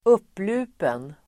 Ladda ner uttalet
Uttal: [²'up:lu:pen]